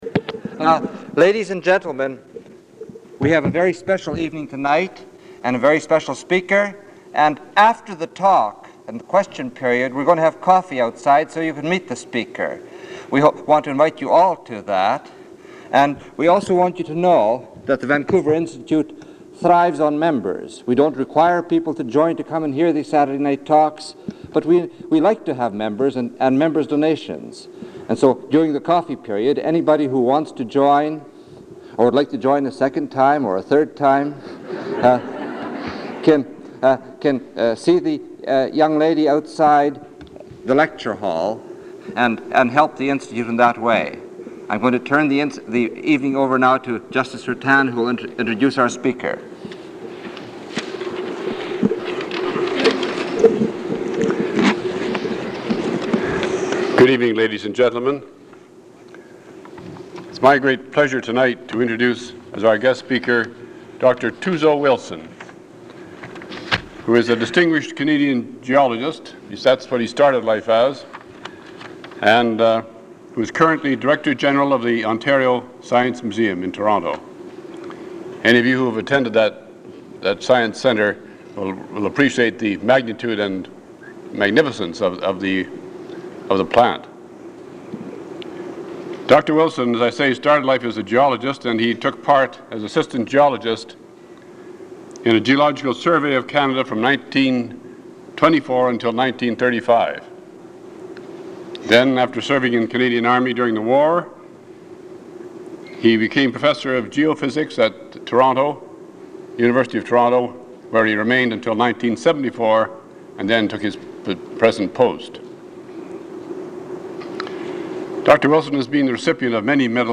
Item consists of a digitized copy of an audio recording of a Vancouver Institute lecture given by J. Tuzo Wilson on February 19, 1977.